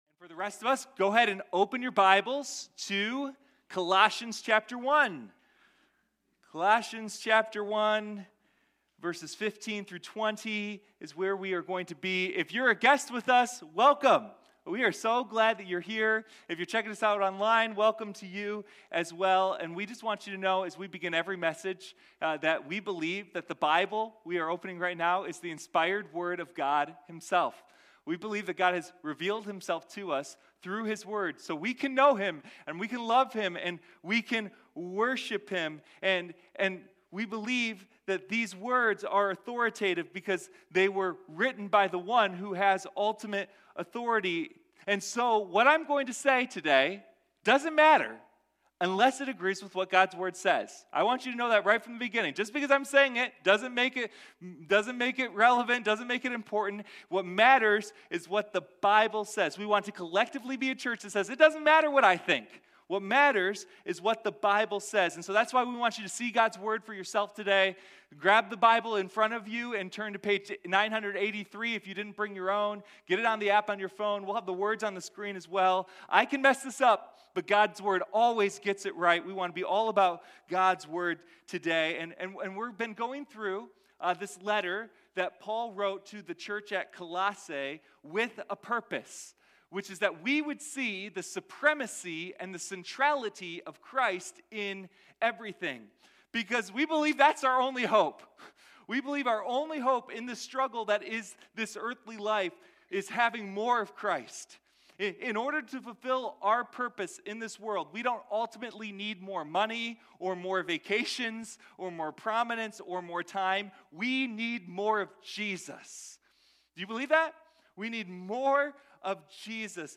Sunday Morning Colossians: His Supremacy | our sufficiency